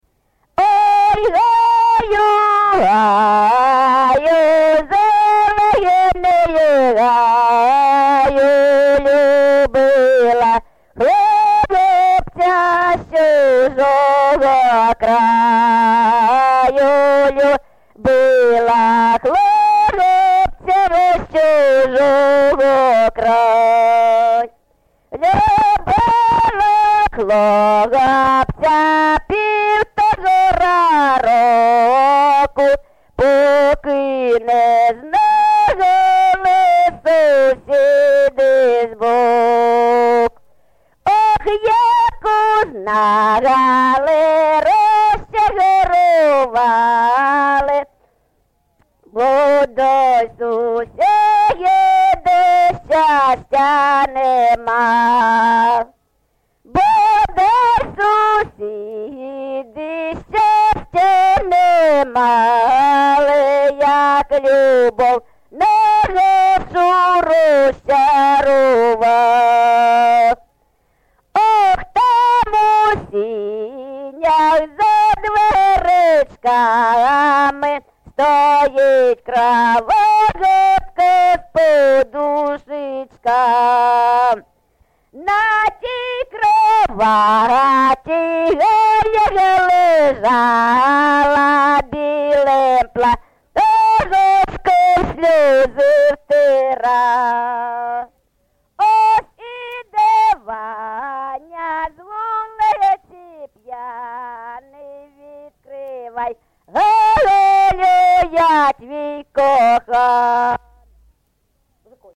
ЖанрПісні з особистого та родинного життя
Місце записус. Свято-Покровське, Бахмутський район, Донецька обл., Україна, Слобожанщина